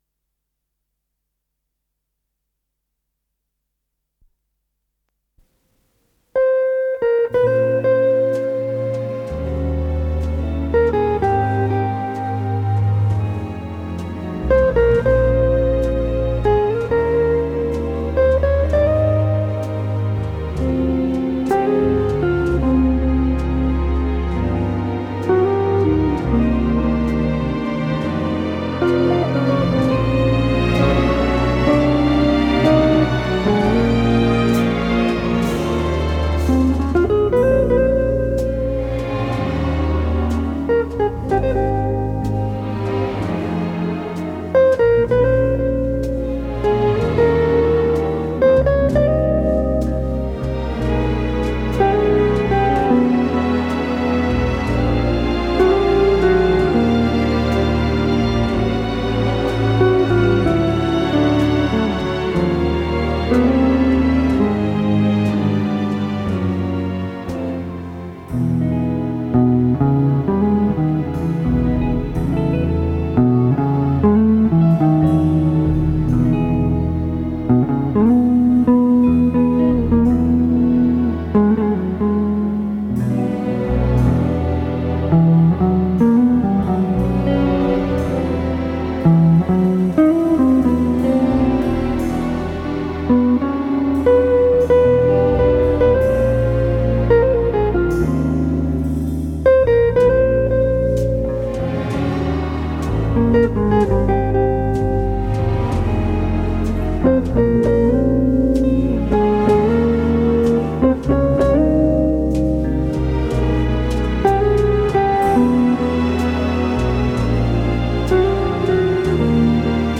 с профессиональной магнитной ленты
гитара